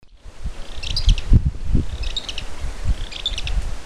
Barullero (Euscarthmus meloryphus)
Nombre en inglés: Fulvous-crowned Scrub Tyrant
Condición: Silvestre
Certeza: Observada, Vocalización Grabada